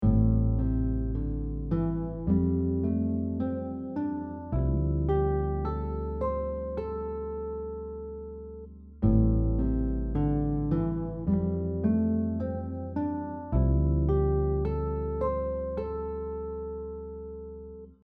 This first example outlines all the notes from shape 5, played over a chord progression written above the staff. It’s designed to help you work on technique, memorization, and developing your ear by hearing the scale notes over a series of 7th chords.